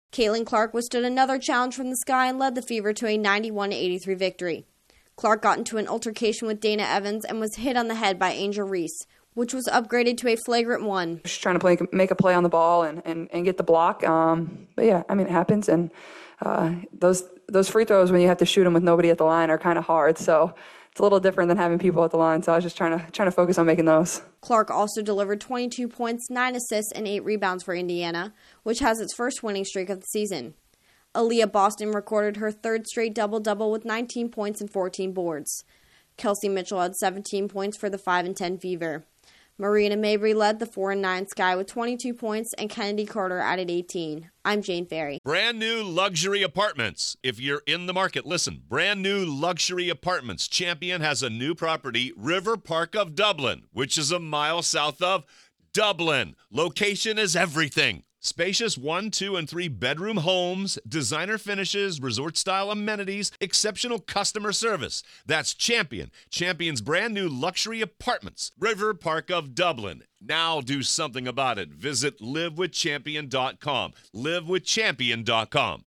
The Fever beat the Sky for the second time this season. Correspondent